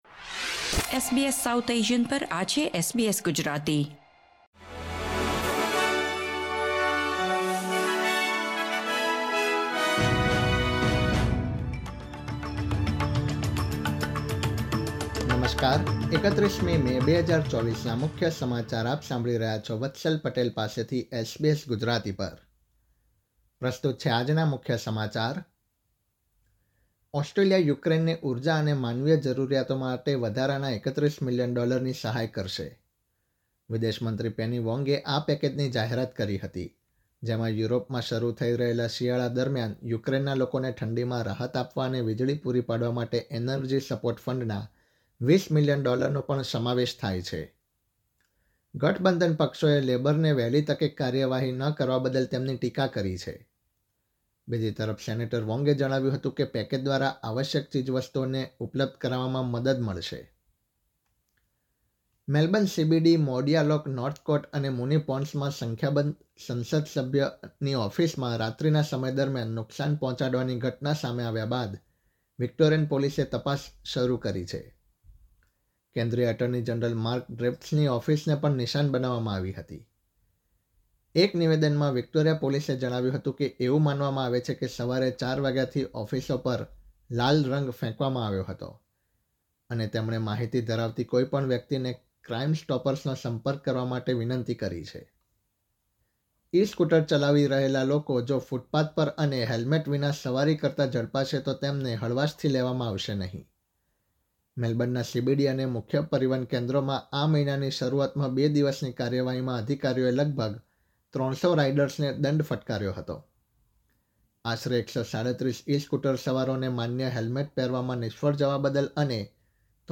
SBS Gujarati News Bulletin 31 May 2024